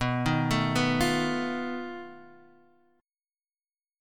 B7b5 chord